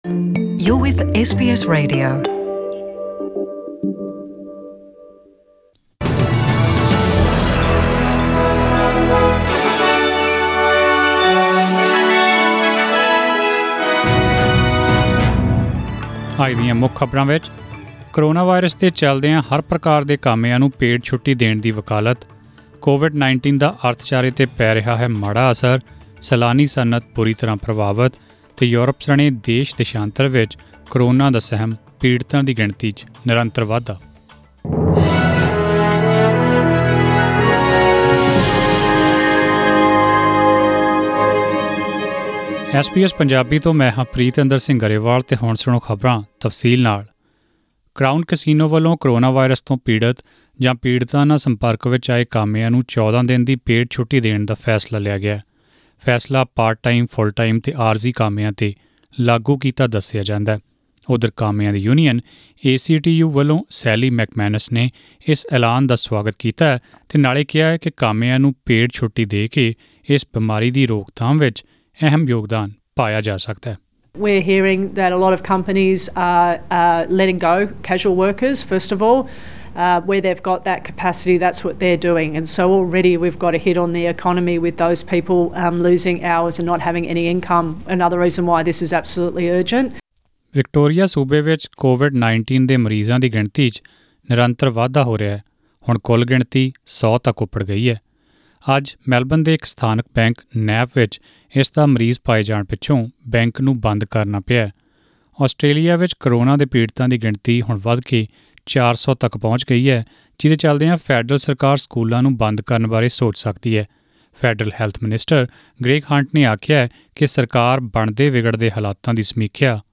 In this bulletin